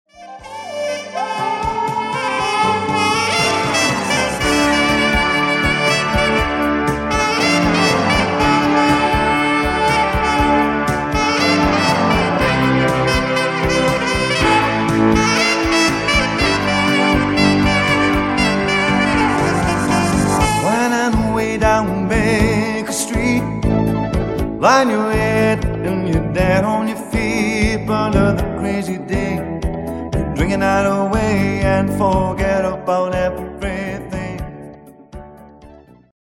Singer saxophonist and entertainer.
Soul Music Samples